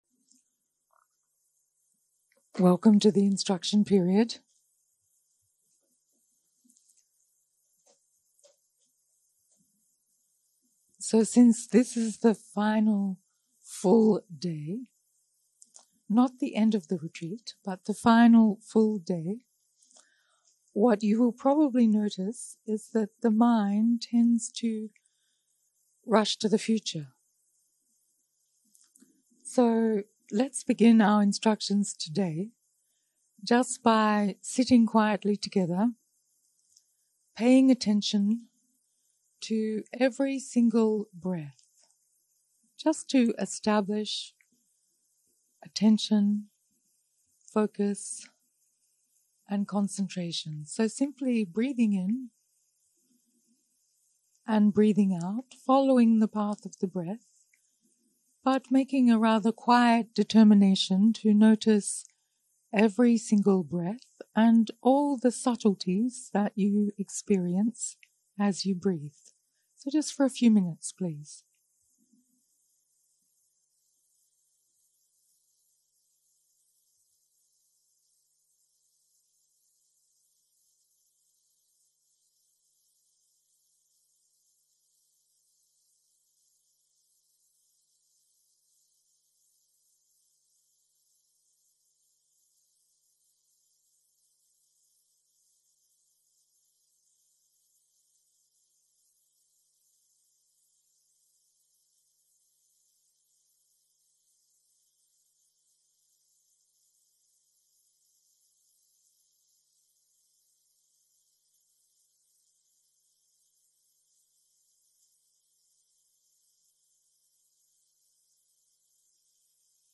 יום 6 - הקלטה 14 - בוקר - הנחיות מדיטציה
סוג ההקלטה: שיחת הנחיות למדיטציה